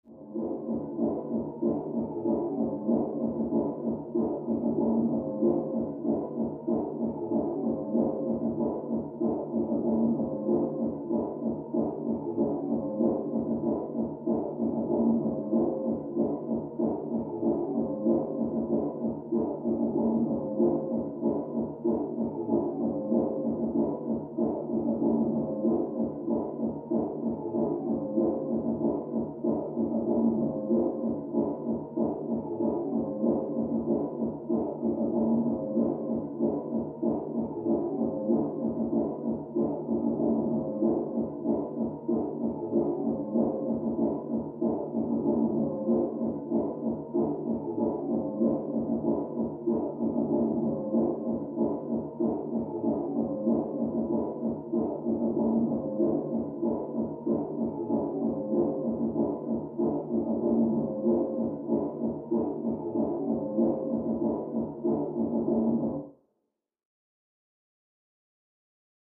Music; Electronic Dance Beat, Through Thin Wall.